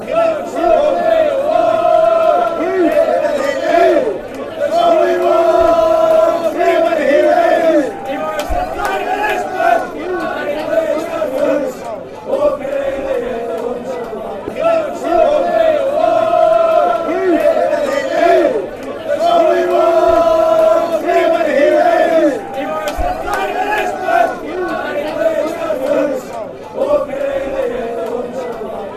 A N. Ireland soccer chant.